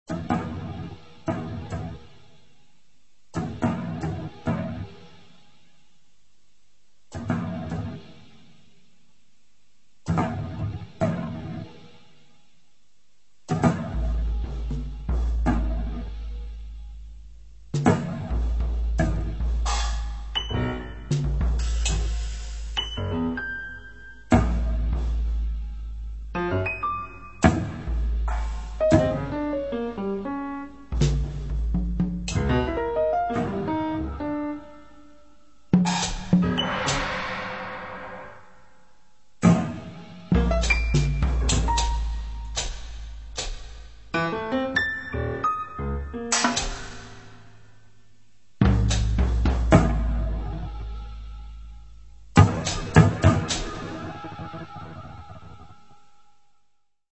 piano and prepared piano
percussion and electronics